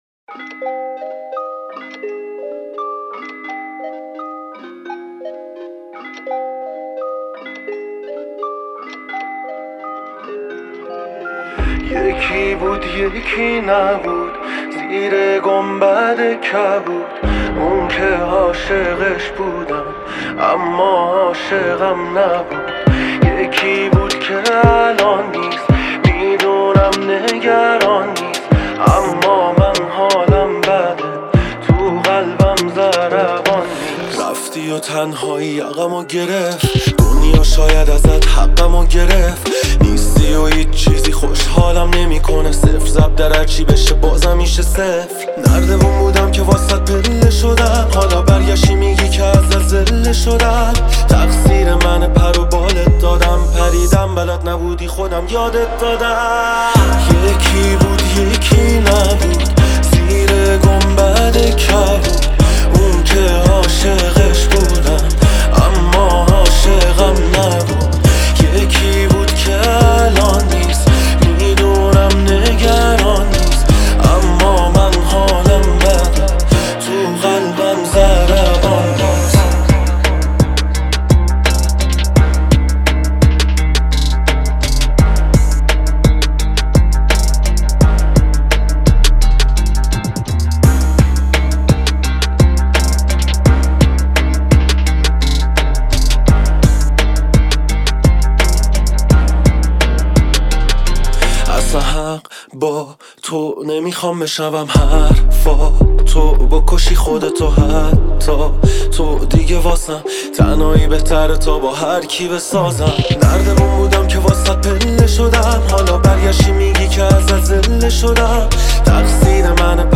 با ریتم 4/4